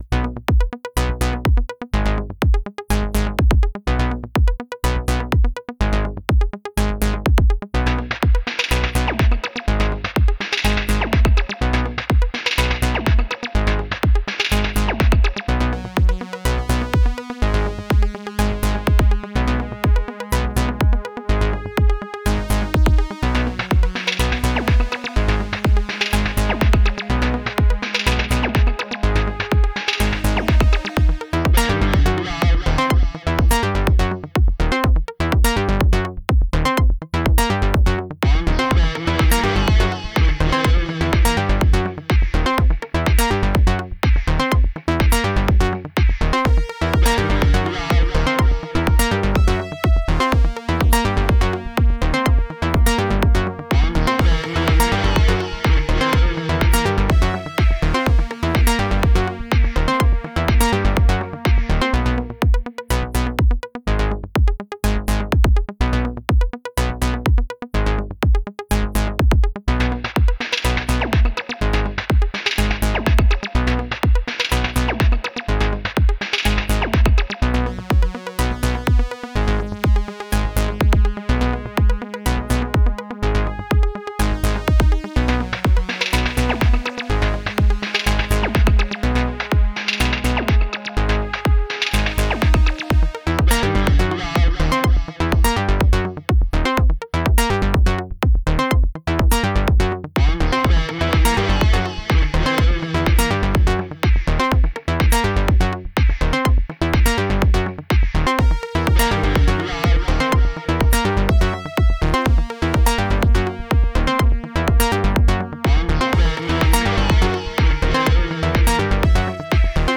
Genre: EBM, IDM.